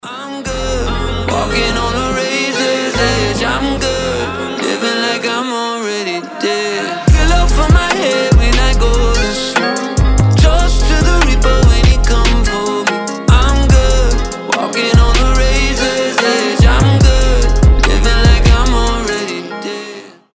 • Качество: 320, Stereo
Хип-хоп
красивый мужской голос
мелодичные
alternative